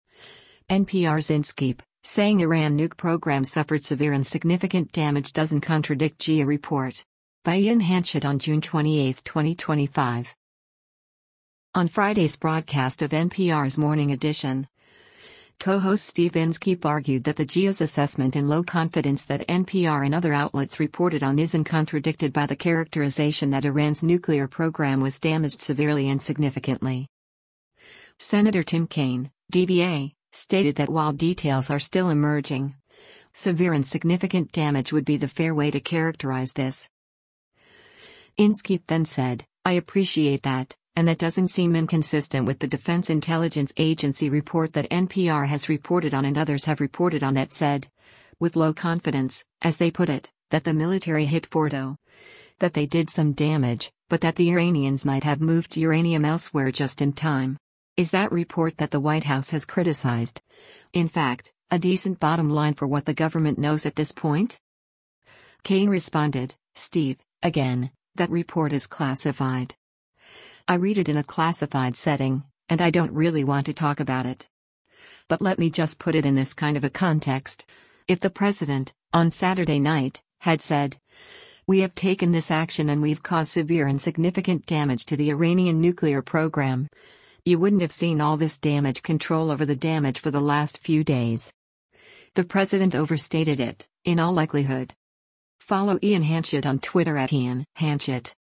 On Friday’s broadcast of NPR’s “Morning Edition,” co-host Steve Inskeep argued that the DIA’s assessment in low confidence that NPR and other outlets reported on isn’t contradicted by the characterization that Iran’s nuclear program was damaged severely and significantly.